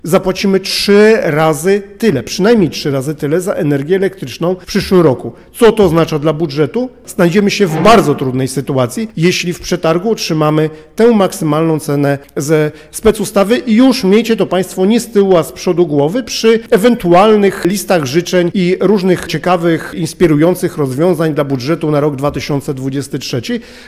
Nie ma tu powodu do optymizmu, mówi prezydent miasta Radosław Witkowski: